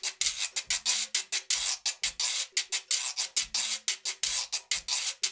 90 GUIRO 3.wav